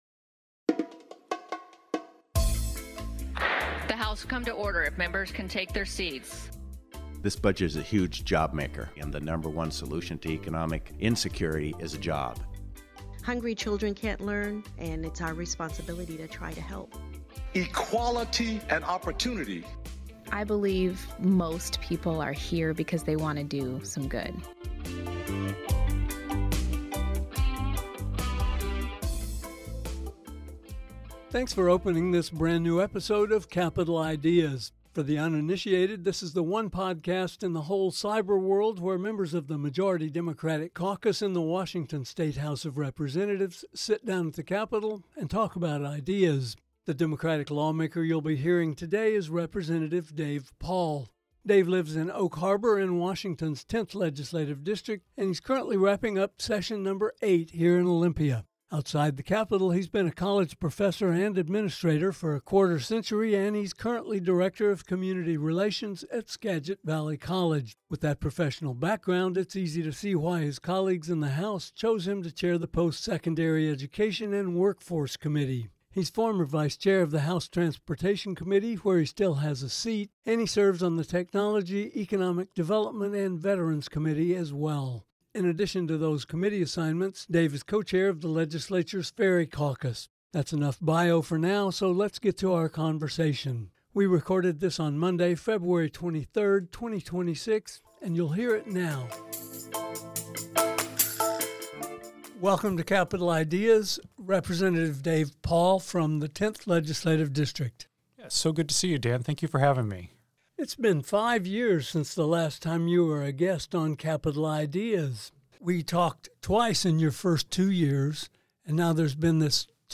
Today, Capitol Ideas welcomes 10th-district Rep. Dave Paul, chair of the House Postsecondary Education and Workforce Committee, for a chat about the 2026 session and much more